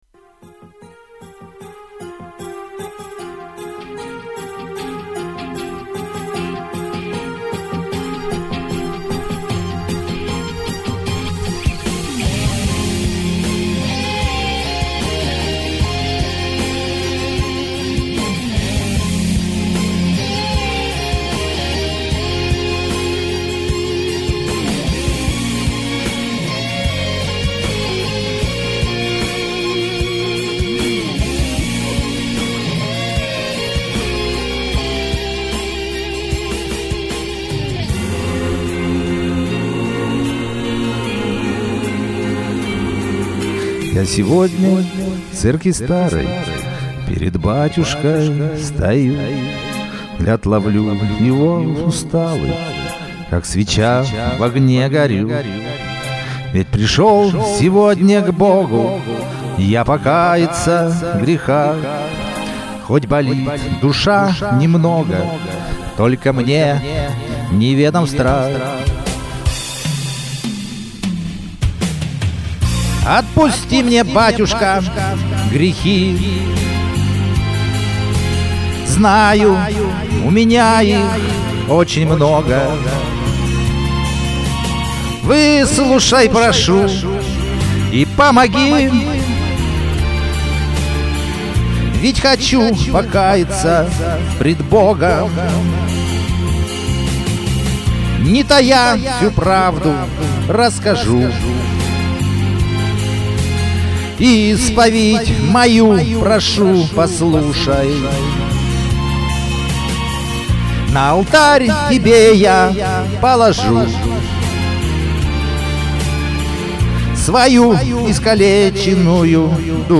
РЕВЕР ПОРТИТ КАРТИНУ...................((((((((((((((